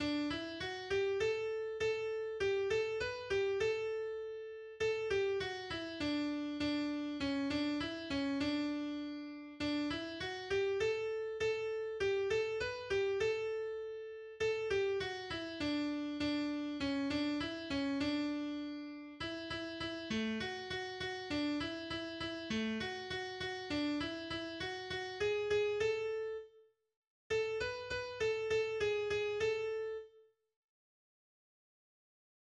03_Regenbogenfarben_Melodie.mp3